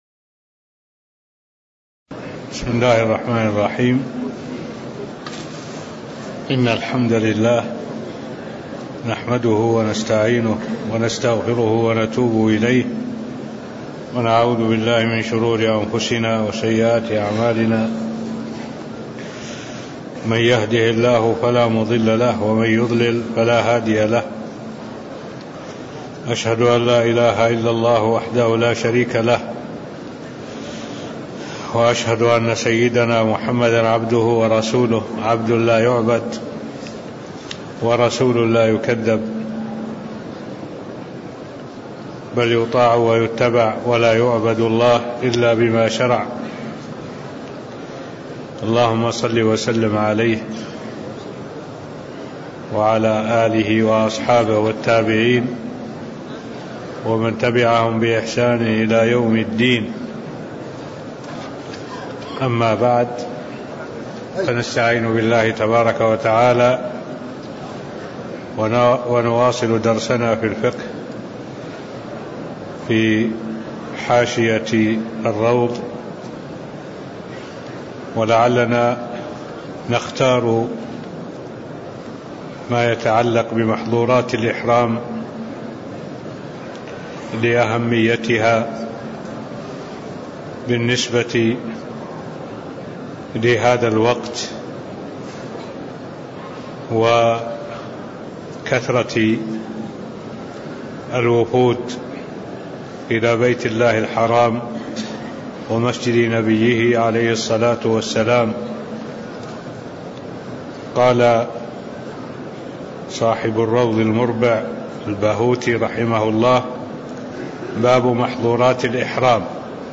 المكان: المسجد النبوي الشيخ: معالي الشيخ الدكتور صالح بن عبد الله العبود معالي الشيخ الدكتور صالح بن عبد الله العبود تكملة باب بيان محظورات الإحرام (04)